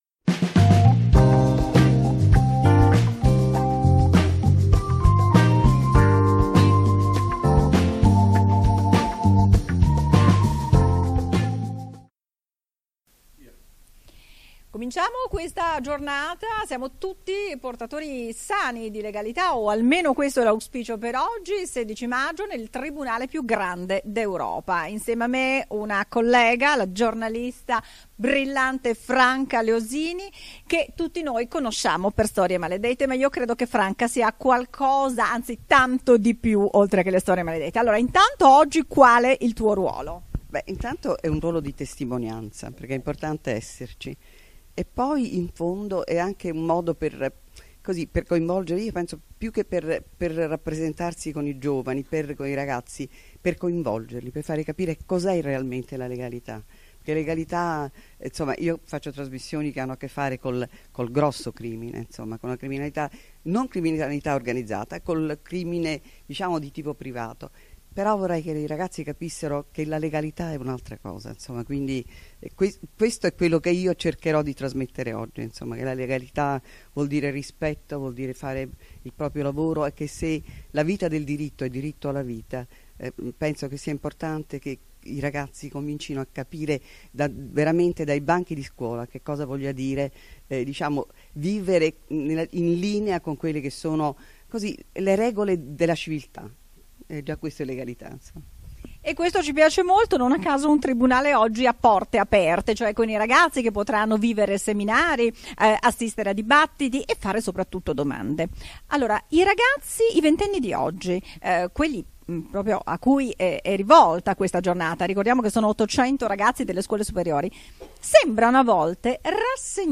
Intervista a Franca Leosini - Notte bianca della legalità
"Portatori sani di legalità - notte bianca della legalità" 16 maggio 2015 Tribunale di Roma (piazzale Clodio).